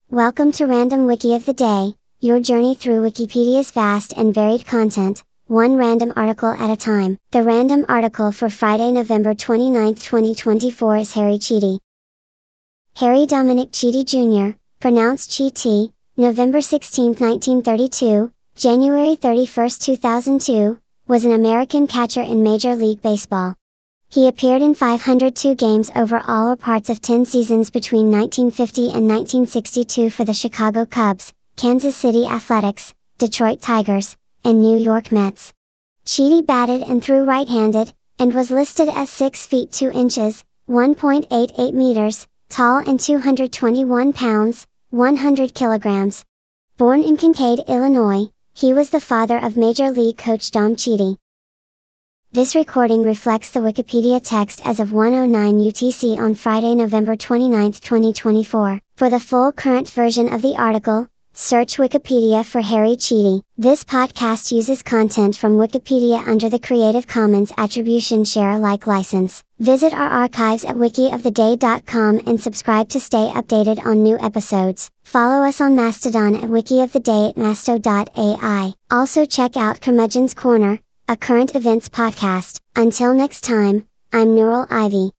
The random article for Friday, 29 November 2024 is Harry Chiti. Harry Dominic Chiti Jr. (pronounced CHEE-tee) (November 16, 1932 – January 31, 2002) was an American catcher in Major League Baseba…